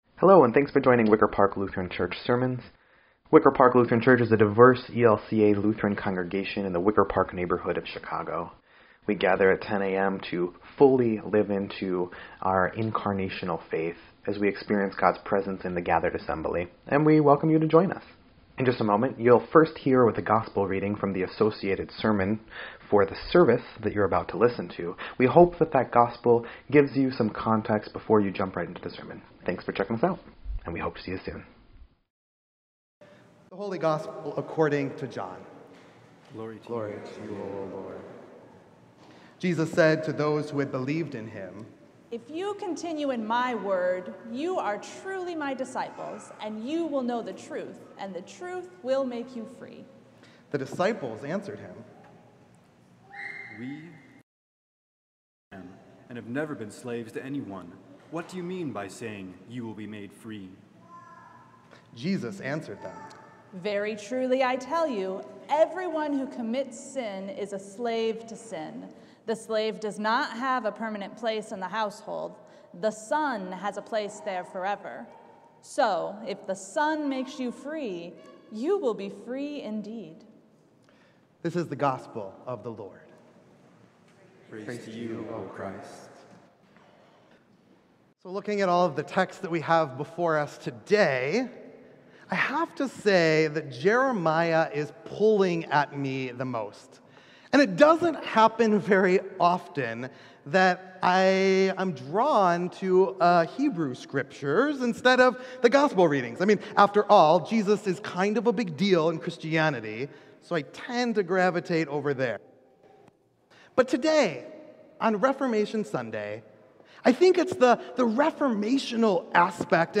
Wicker Park Lutheran Church